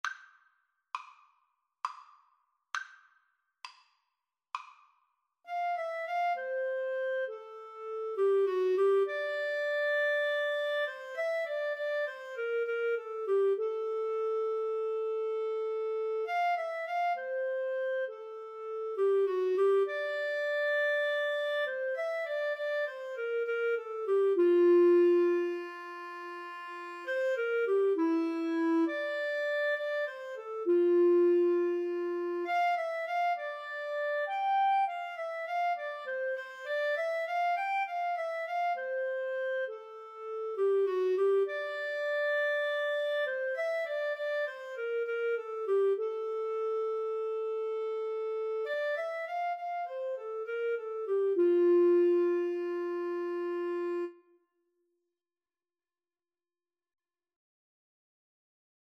Moderato
9/8 (View more 9/8 Music)